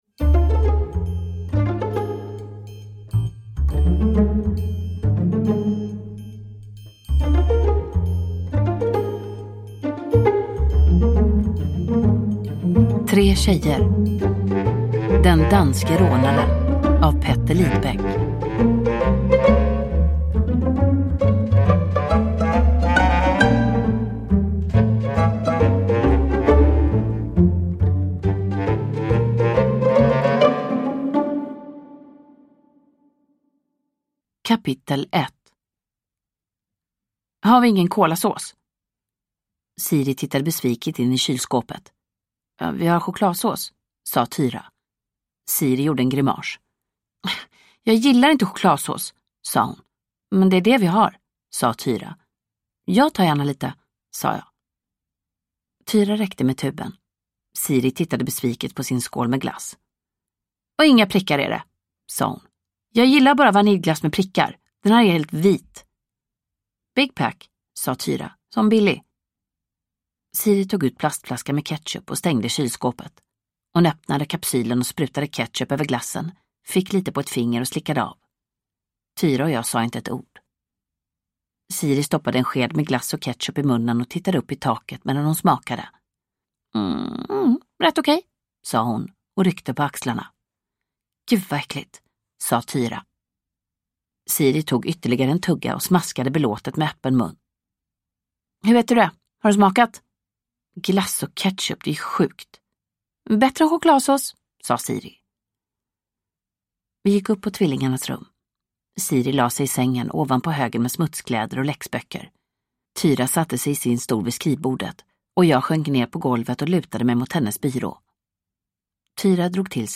Den danske rånaren – Ljudbok – Laddas ner